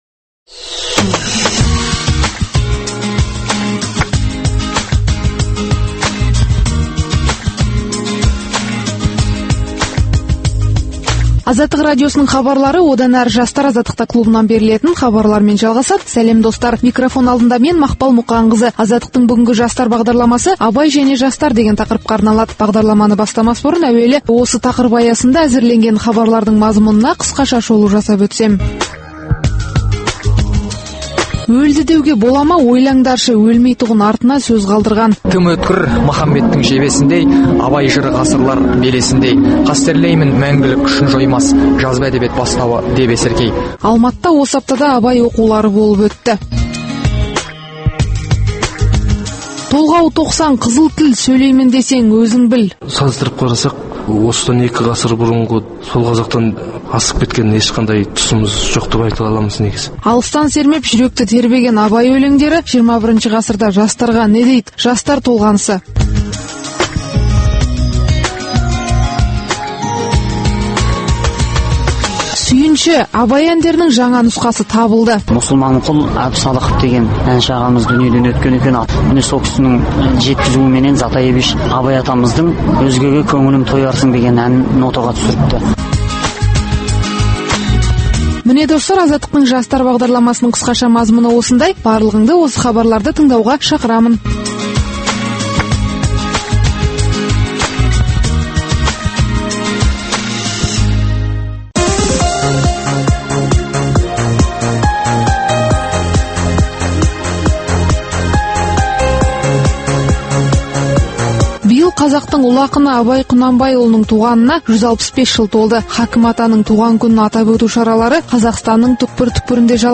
“Абай және жастар” Азаттық радиосының бүгінгі бағдарламасы осы тақырыпқа арналады. Бағдарламаның алғашқы бөлігінде Алматыда өткен Абай оқуларынан репортаж тыңдайсыздар. Оқушылар, жастар Абайға арнаған арнау өлеңдерін оқиды. Бағдарламаның екінші бөлігінде жастар әдебиетінің өкілдері Абай заманы мен қазіргі уақытты қатар қояды.